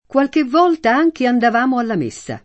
messa [m%SSa] s. f. («funzione religiosa») — es. con acc. scr.: qualche volta anche andavamo alla méssa [